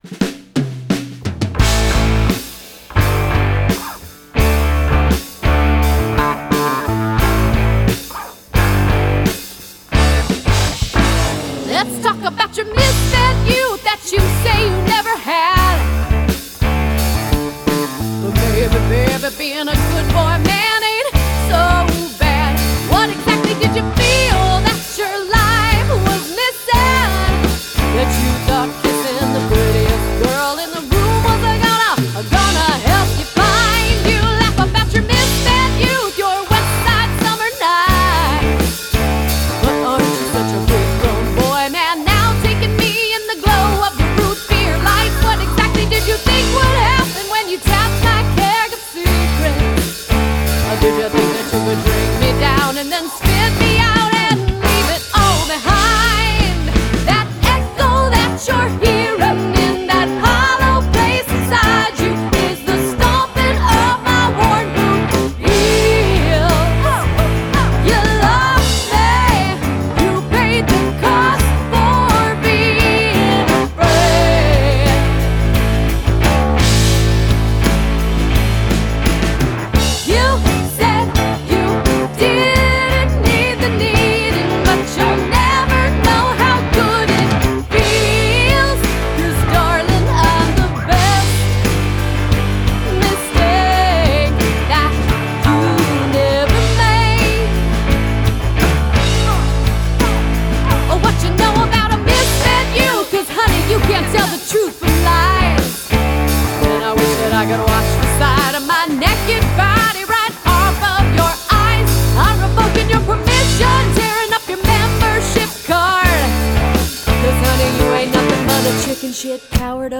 Жанр: Pop, Indie Pop, Country, Singer-Songwriter